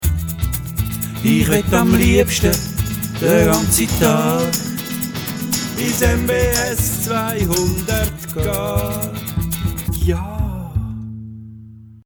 Der Klingelton zum Modul